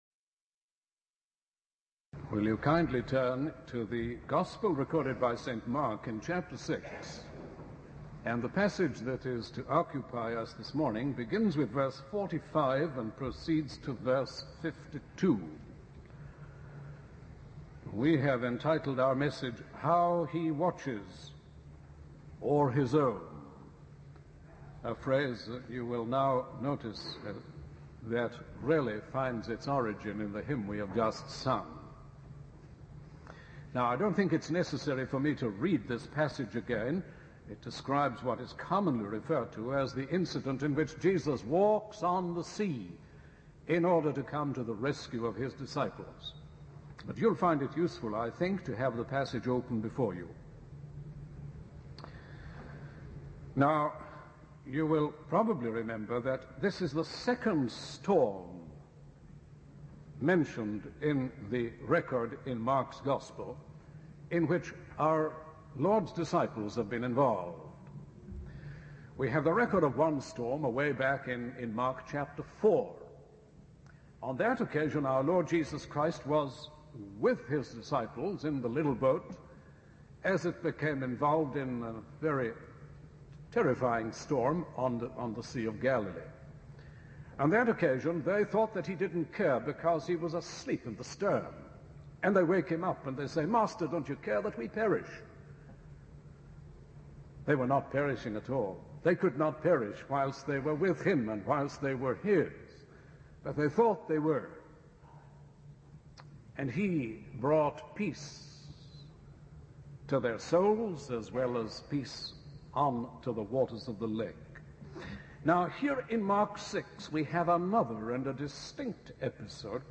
In this sermon, the preacher emphasizes the constant vigilance and watchfulness of Jesus over his followers.